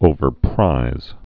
(ōvər-prīz)